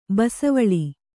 ♪ basavaḷi